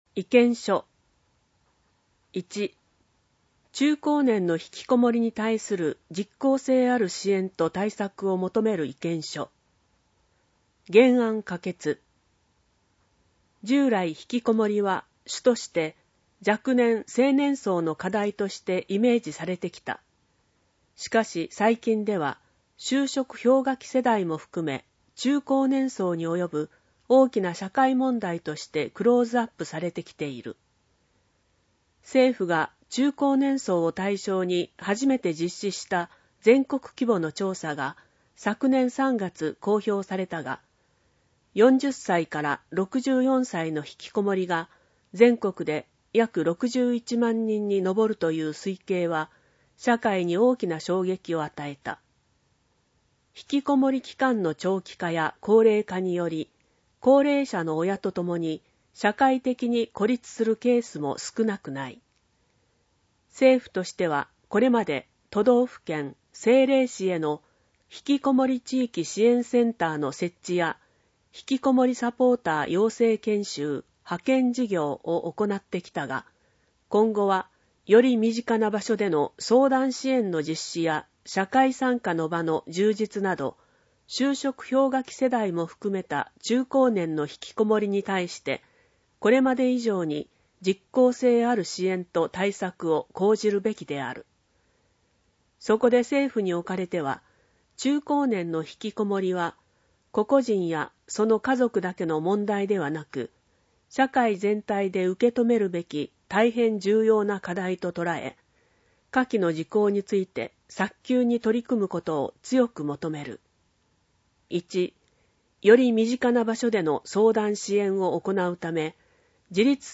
音声訳版 議会だより第57号